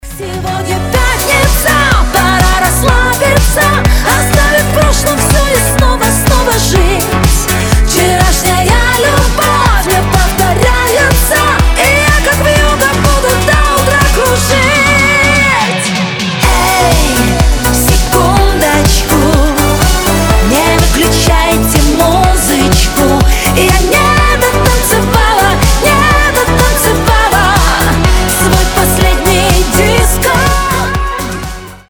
• Качество: 320, Stereo
поп
позитивные
громкие
зажигательные
веселые